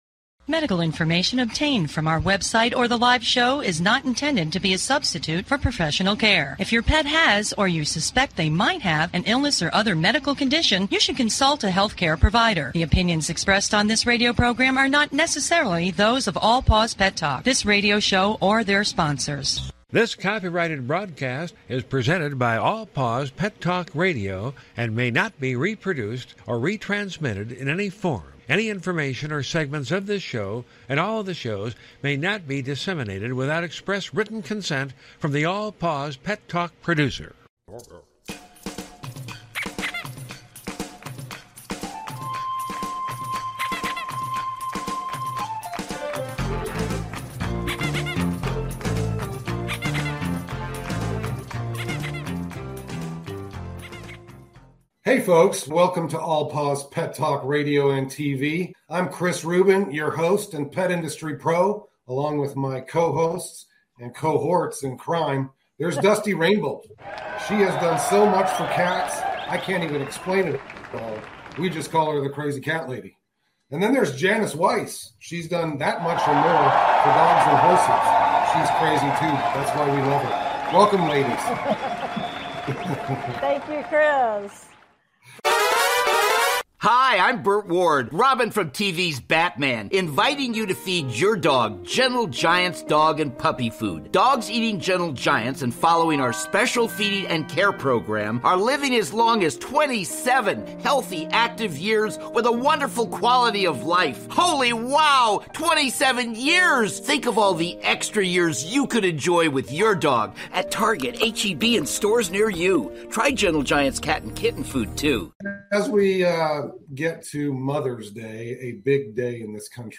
Talk Show Episode, Audio Podcast, All Paws Pet Talk and with The Experts on , show guests , about All Paws Pet Talk,The Experts, categorized as Arts,Business,Plant & Animals,Entertainment,Kids & Family,Pets and Animals,Self Help,Society and Culture,TV & Film
Our hosts are animal industry professionals covering various specialty topics and giving free pet behavior and medical advice. We give listeners the opportunity to speak with animal experts one on one.